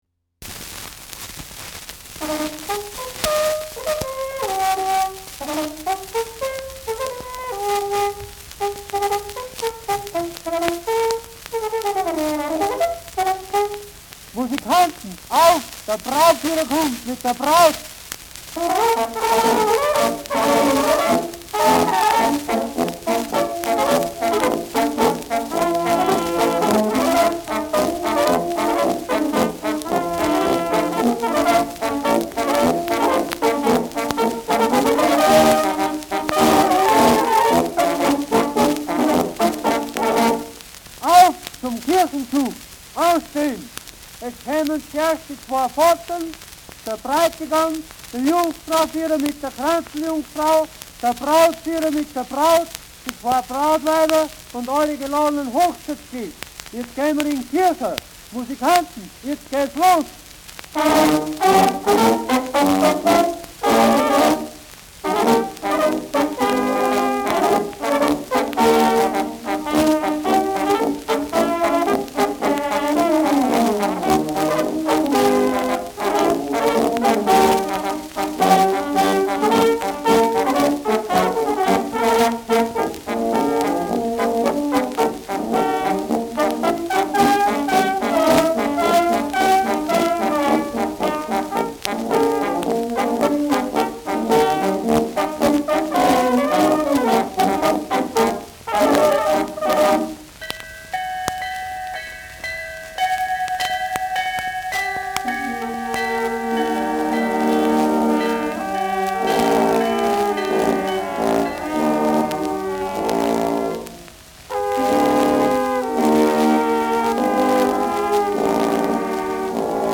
Schellackplatte
Knacken zu Beginn : präsentes Knistern : abgespielt : leichtes Leiern
Andorfer Bauernkapelle (Interpretation)
Szenen aus den Ritualen einer Bauernhochzeit mit Zwischentexten des Prokurators / Hochzeitsladers / Zeremonienmeisters.
[Salzburg?] (Aufnahmeort)